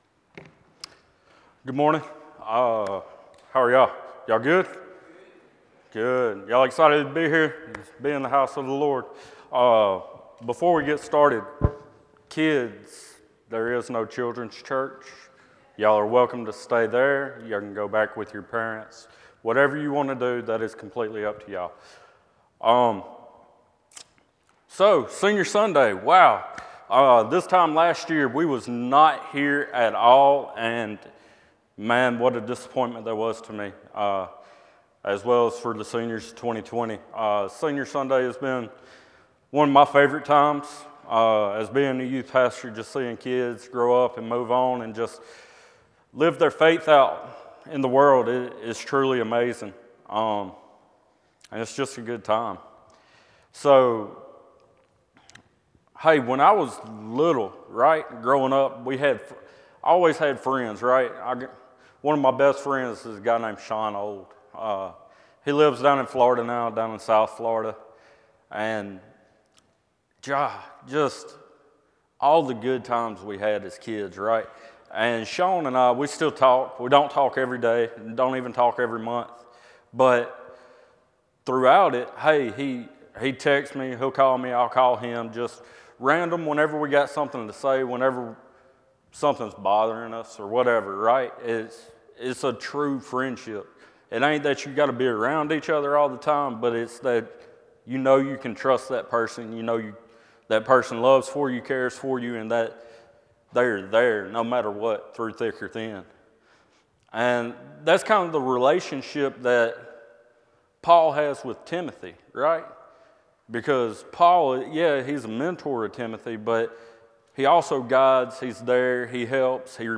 Morning Service - Reminded | Concord Baptist Church
Sermons - Concord Baptist Church